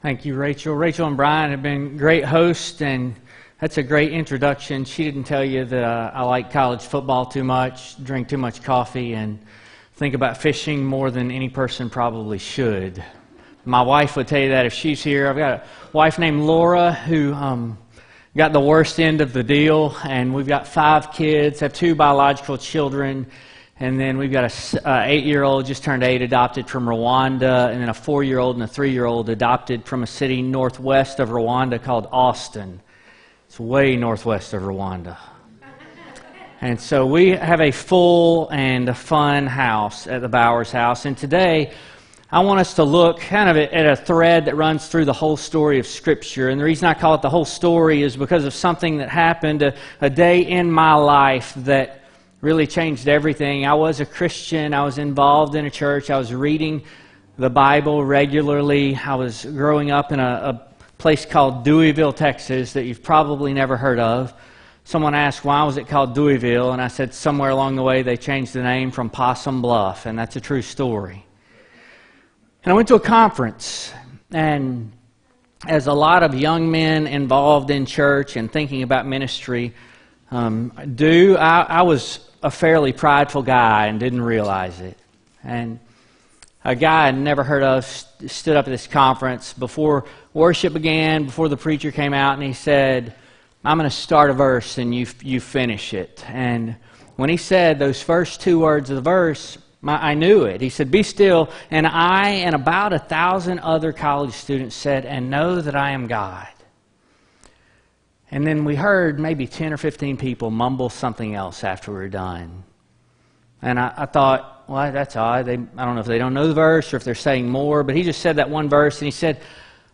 10-20-18 sermon